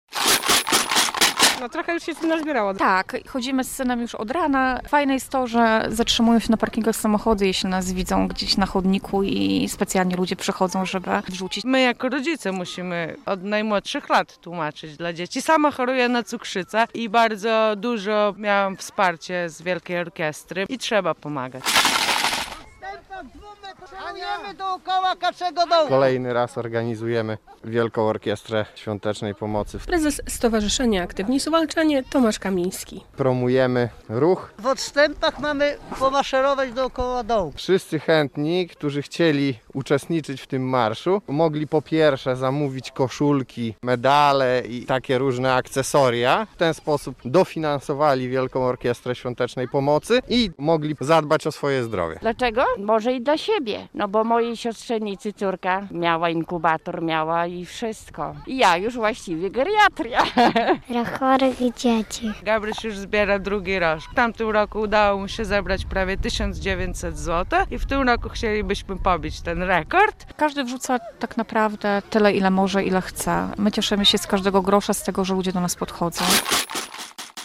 Około 140 wolontariuszy kwestuje w Suwałkach podczas tegorocznego finału WOŚP - relacja